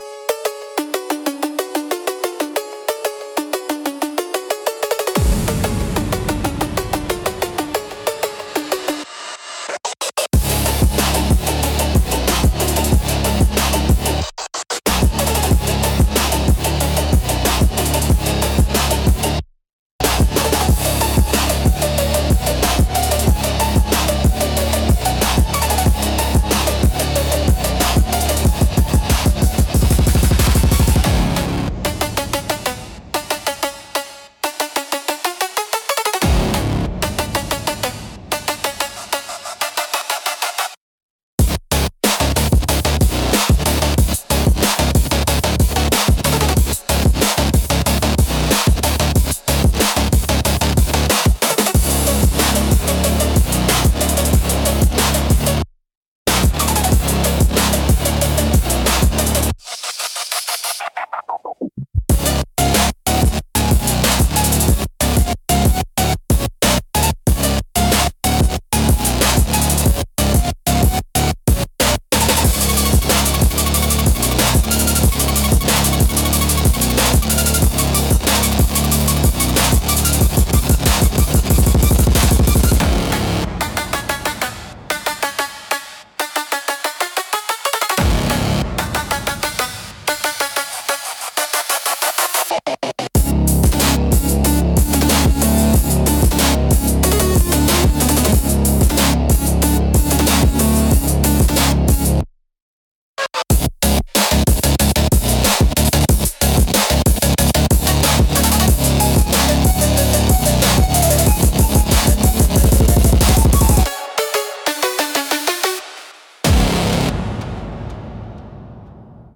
Genre: Phonk Mood: Video Game Vibes Editor's Choice